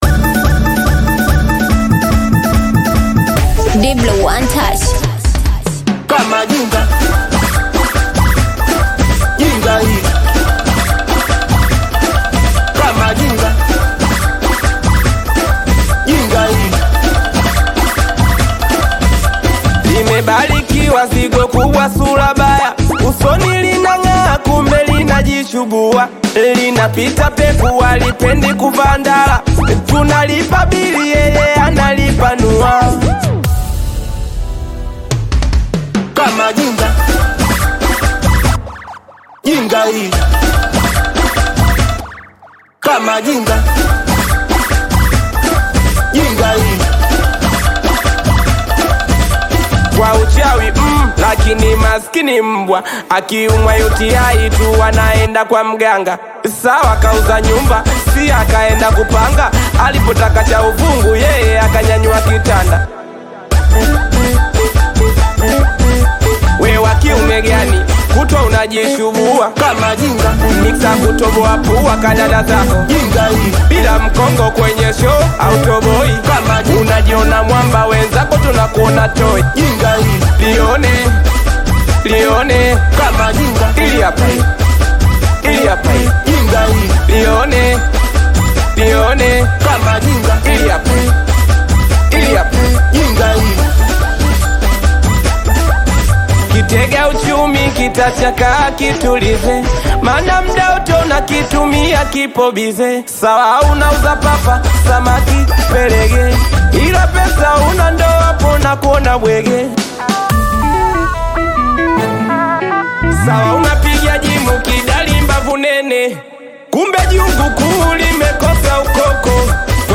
Tanzanian Bongo Flava Singeli